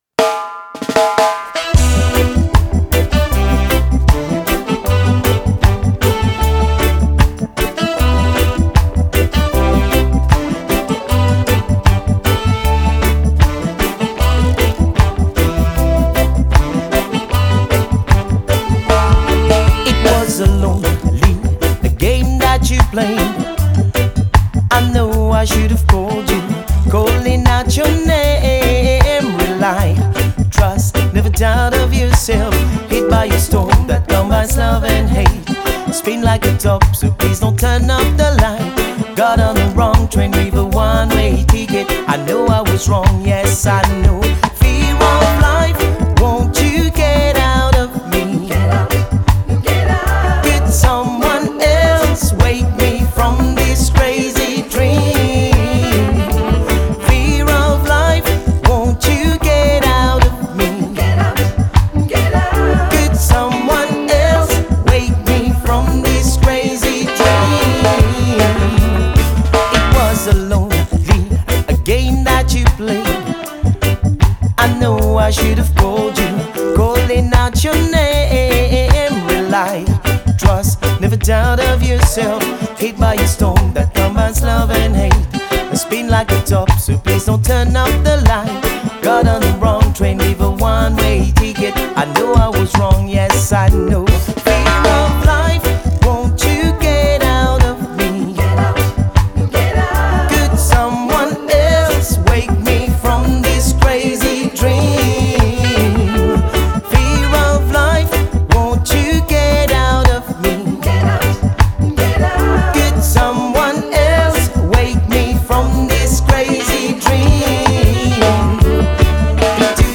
lead singer and percussionist